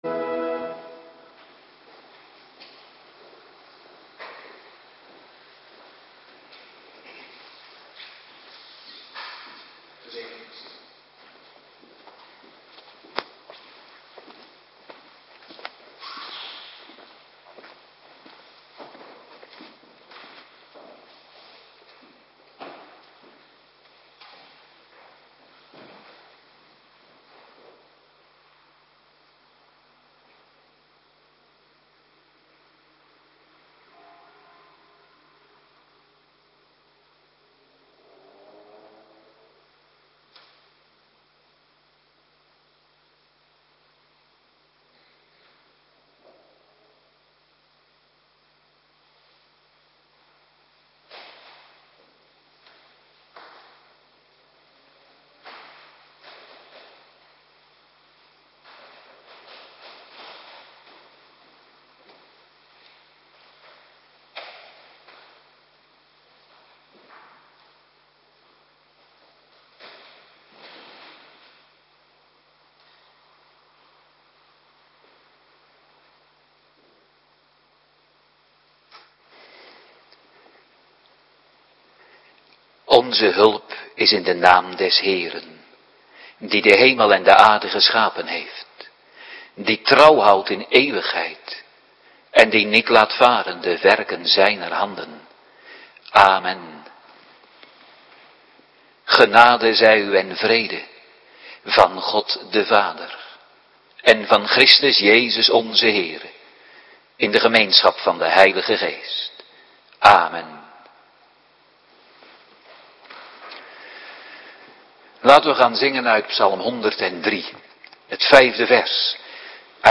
Woensdagavonddienst